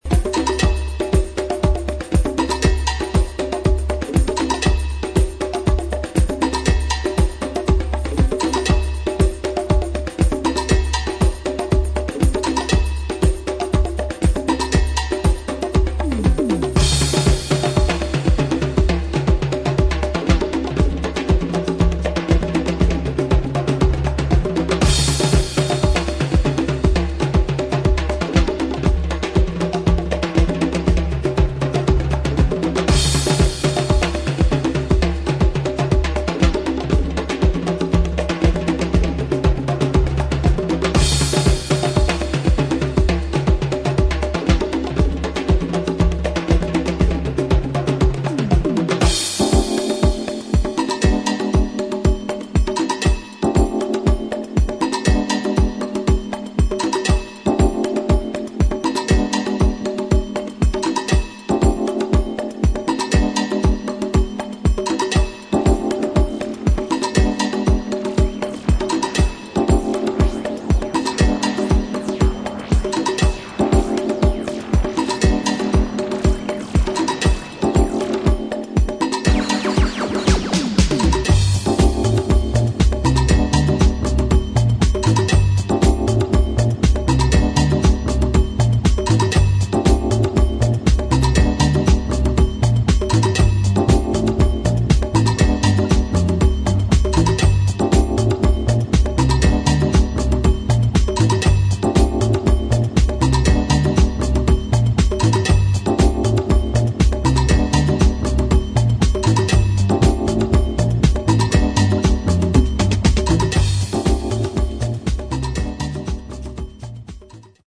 [ DISCO / BRAZIL ]